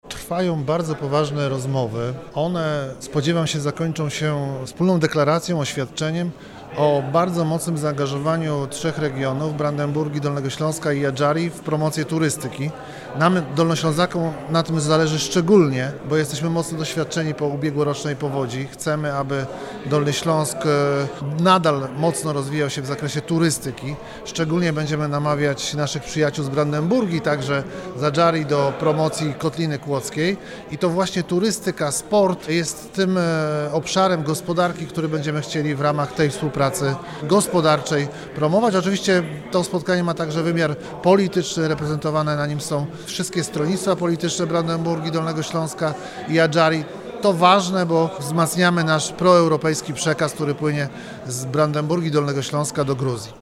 W sali Sejmiku Województwa Dolnośląskiego we Wrocławiu odbyło się trójstronne spotkanie prezydiów parlamentów regionalnych Dolnego Śląska, Autonomicznej Republiki Adżarii oraz Kraju Związkowego Brandenburgii.